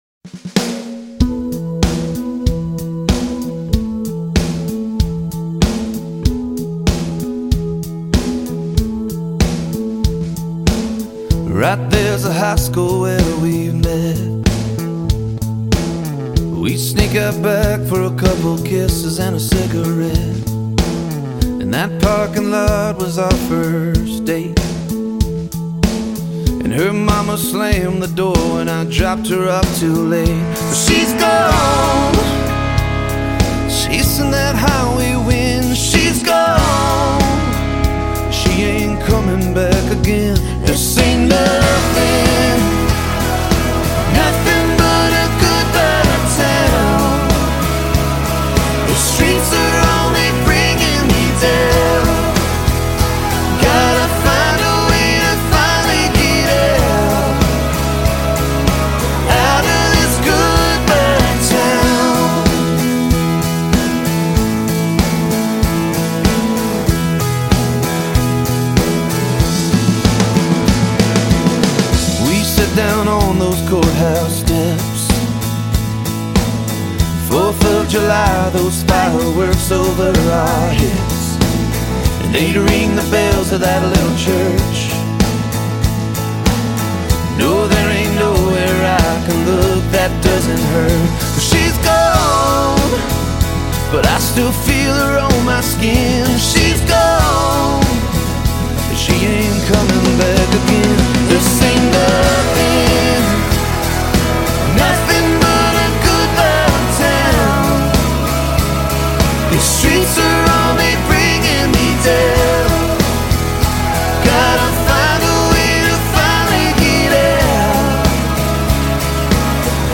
American Country music trio